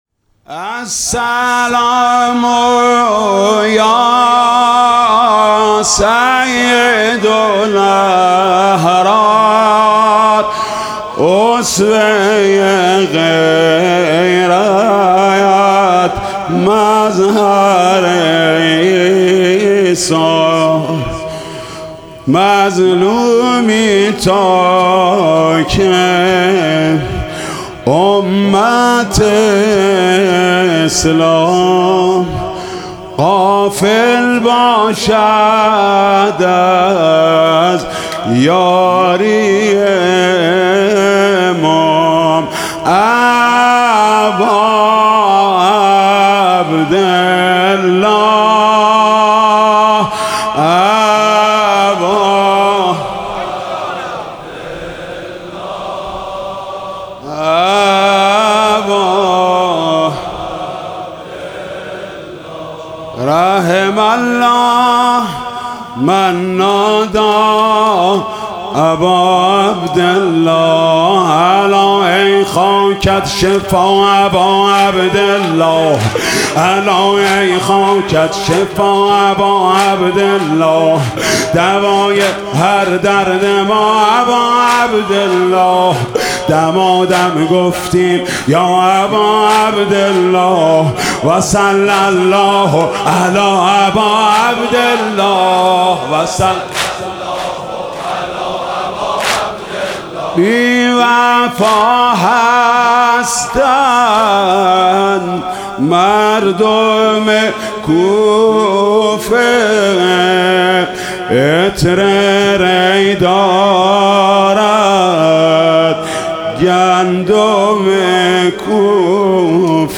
مداحی واحد شب اول محرم 1404